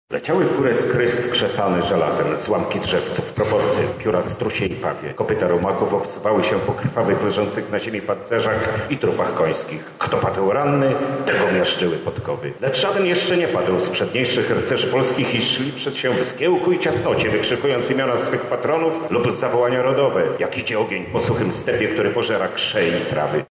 Podjął się recytacji fragmentu „Krzyżaków” Henryka Sienkiewicza:
Wydarzenie odbyło się w Wojewódzkiej Bibliotece Publicznej im. Hieronima Łopacińskiego w Lublinie w ramach tegorocznej Ogólnopolskiej Nocy Bibliotek.